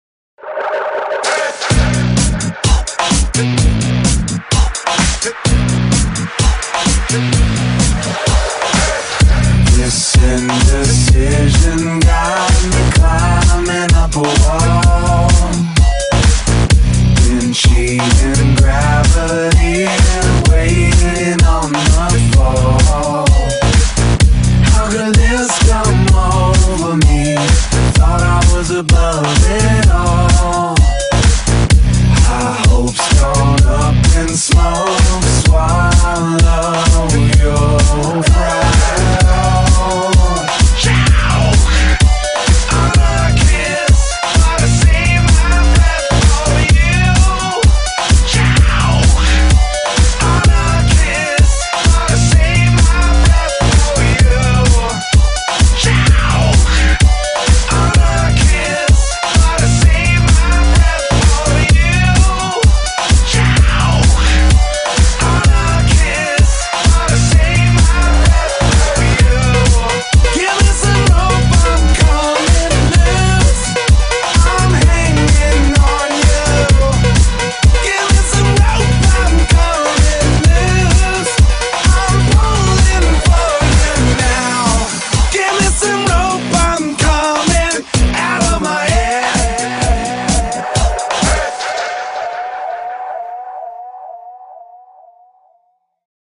BPM128
Audio QualityPerfect (High Quality)
alternative rock